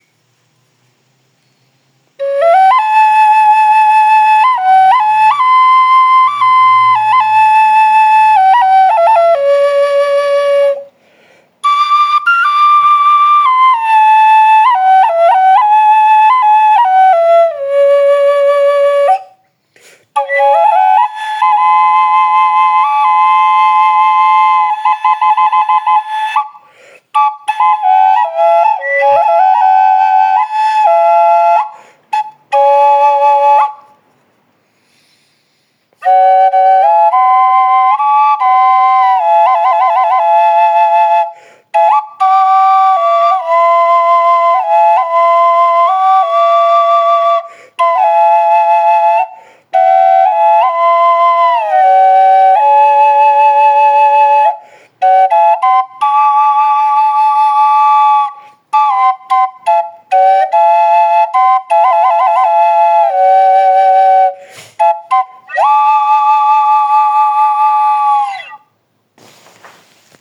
Flauta Nativa Rupestre Sembrador
• Madera de Alta Calidad: La flauta está hecha con madera de la más alta calidad, seleccionada cuidadosamente para garantizar un sonido claro y una resonancia excepcional.
• Tonalidad Encantadora: La flauta está afinada en RE (D) para producir una tonalidad encantadora que te transportará a los paisajes y la cultura de Barichara.
flautanativasonidodoble.mp3